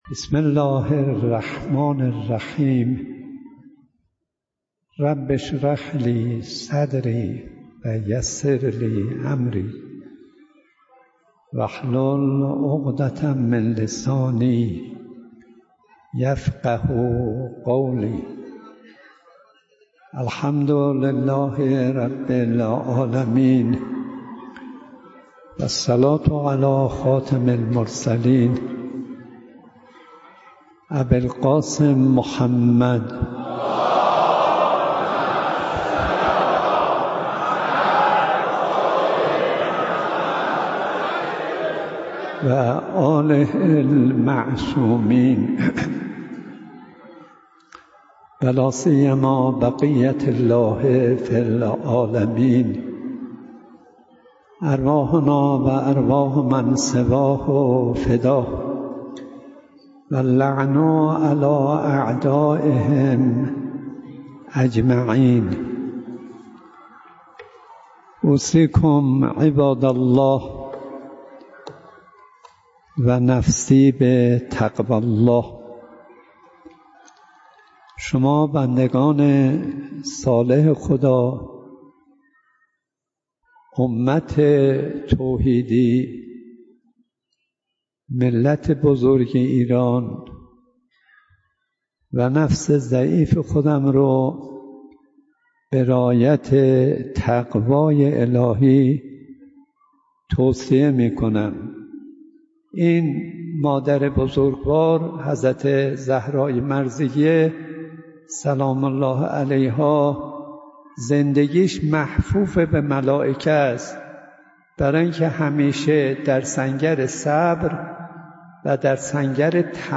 کاظم صدیقی در محضر استاد ۲۲۹ درس اخلاق آیت الله صدیقی؛ ۸ اردیبهشت ۱۴۰۳ در حال لود شدن فایل های صوتی...